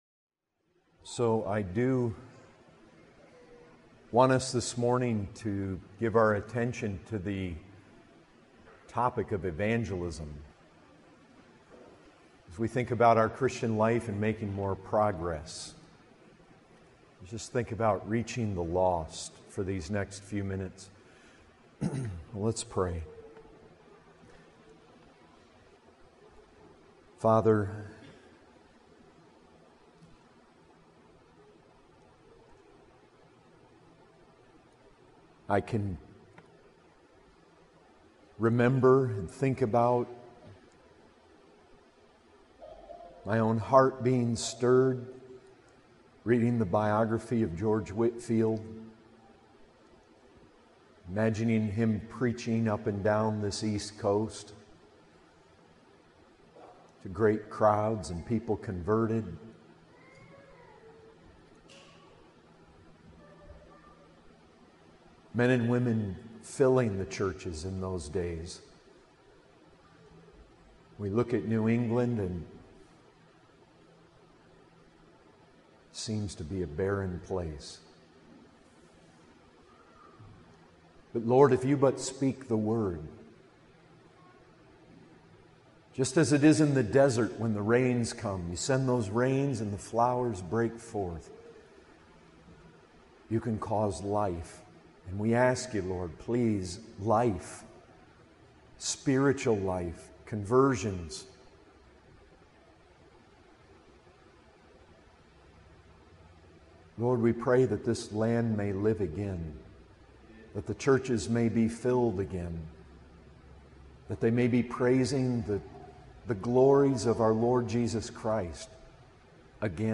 2018 Category: Full Sermons Topic